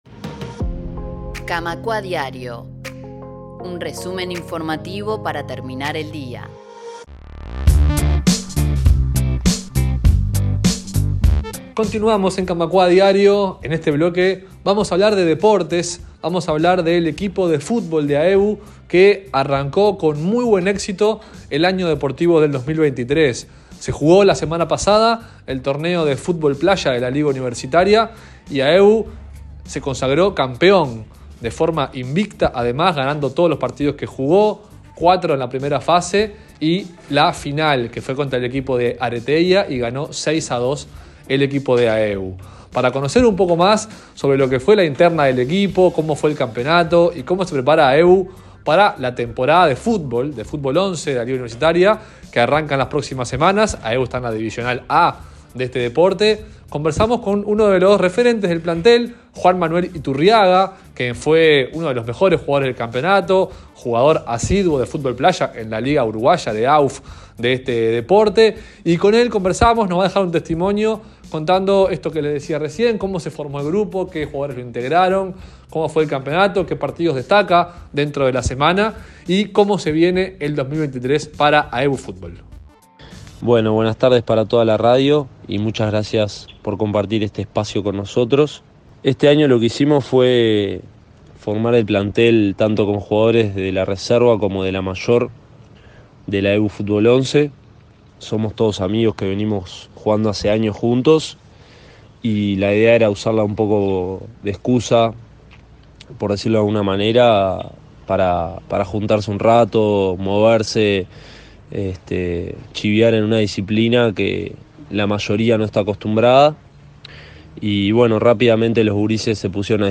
En Camacuá Diario conversamos con uno de los integrantes del plantel